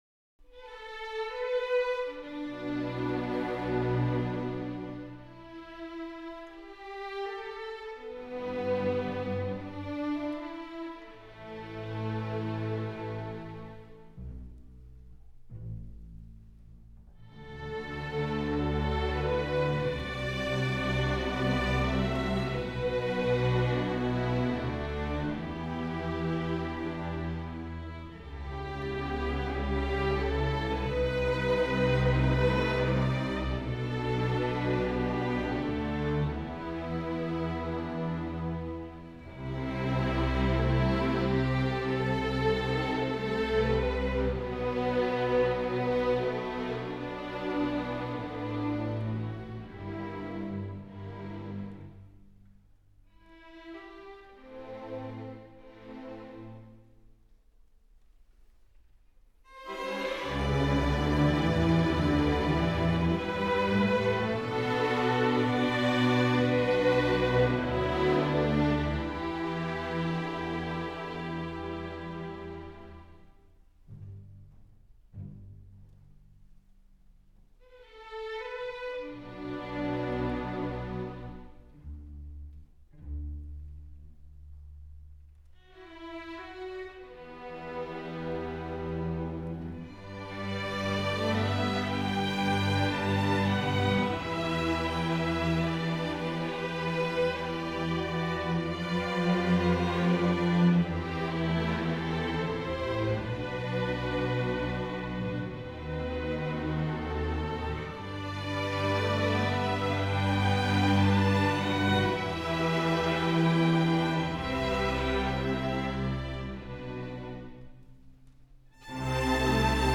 诡诵多变、气势惊人
颠覆过时的思考窠臼，以充满哲理的音乐结构及稳健扎实的音乐律动，让您全身的聪明细胞活起来，于企划之始就立于不败之地。